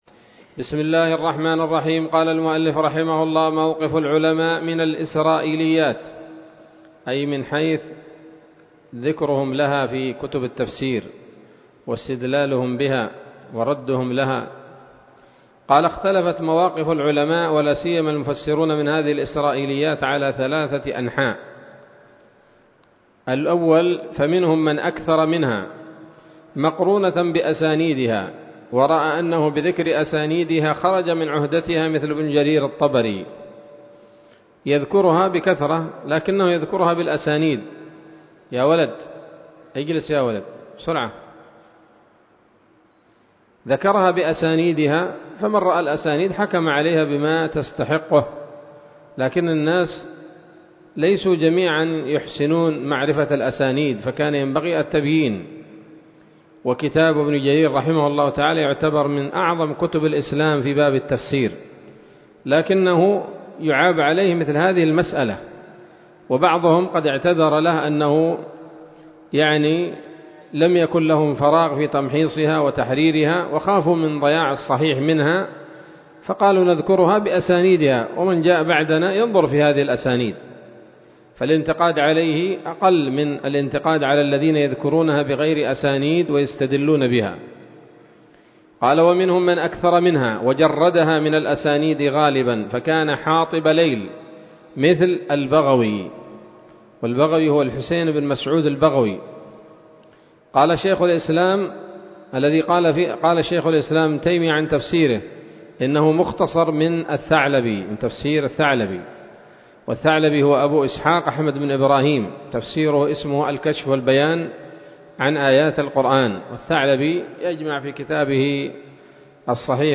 الدرس الرابع والثلاثون من أصول في التفسير للعلامة العثيمين رحمه الله تعالى 1446 هـ